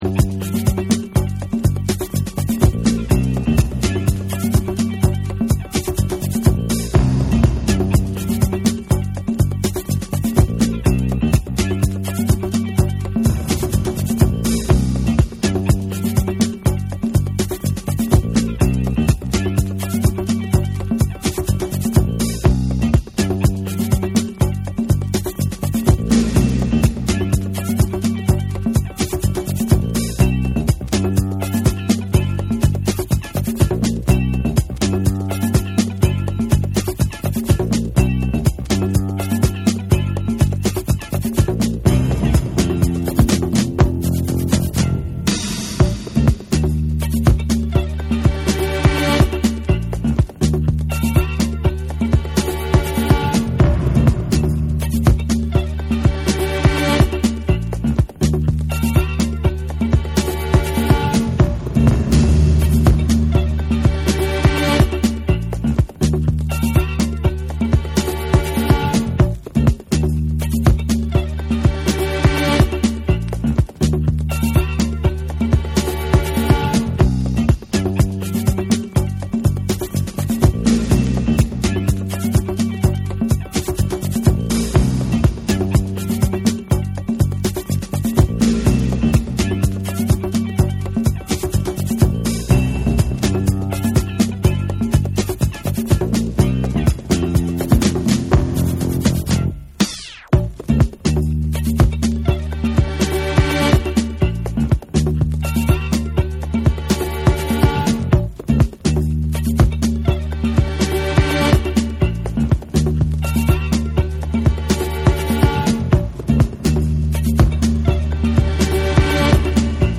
TECHNO & HOUSE / RE-EDIT / MASH UP